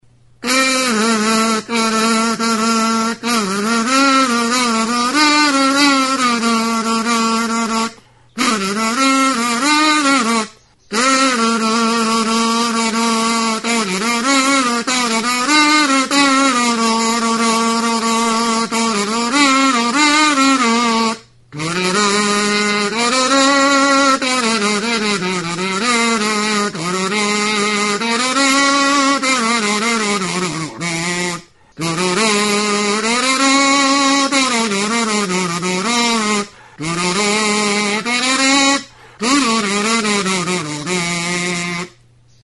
Membranófonos -> Mirliton
Grabado con este instrumento.
Kanabera eta zigarro paperekin egindako 'mirliton' gisako turuta da.